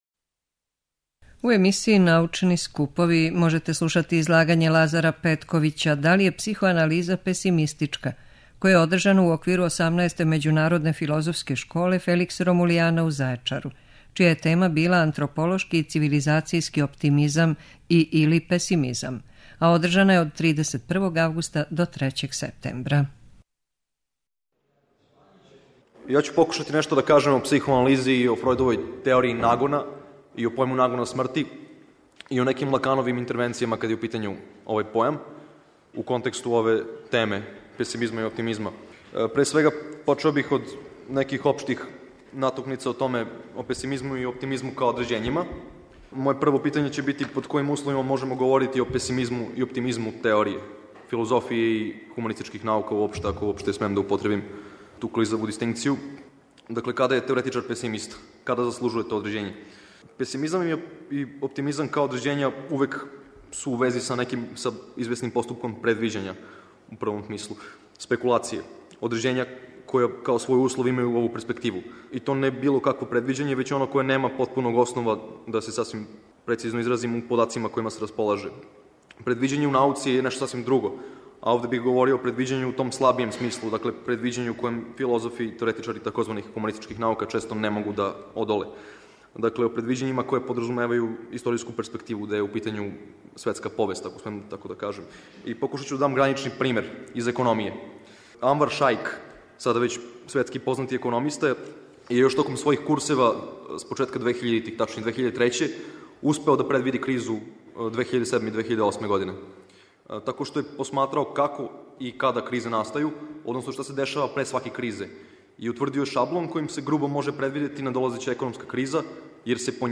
Следећег петка, 10. новембра, наставићемо емитовање звучних записа са овог научног скупа.